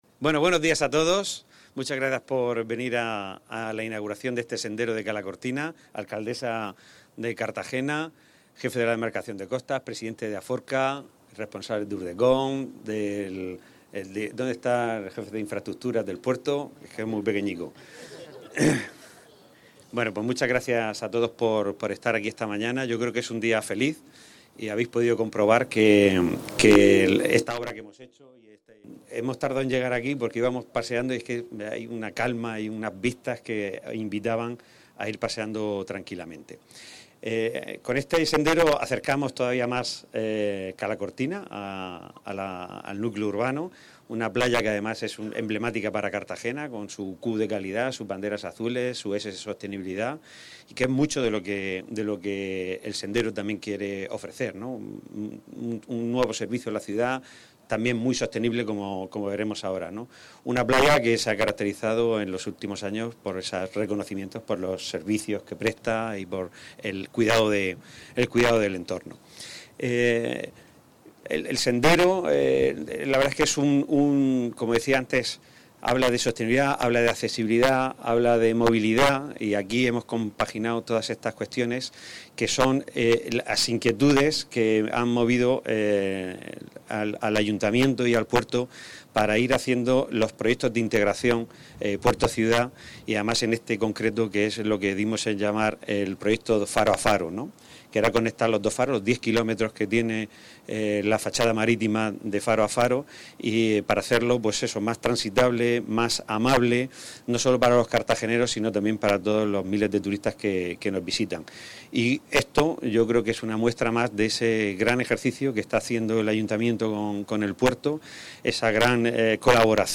Enlace a Declaraciones de Pedro Pablo Hernández y Noelia Arroyo